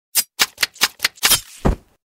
5. Убийство ножом among us
5-ubiistvo-nozhom-among-us.mp3